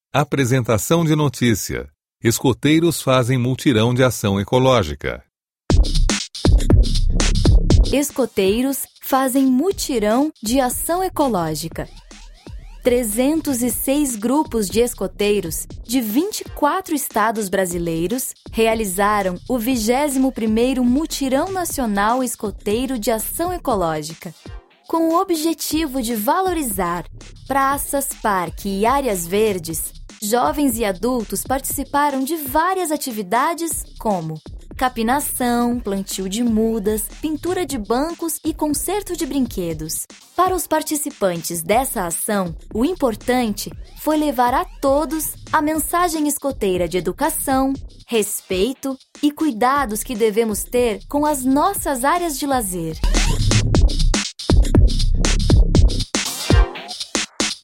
Apresentação de notícia "Escoteiros fazem mutirão de ação ecológica"